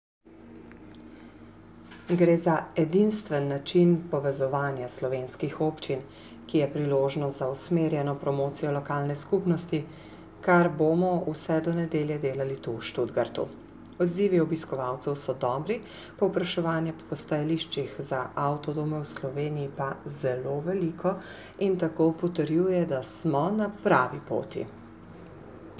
pripeta tonska izjava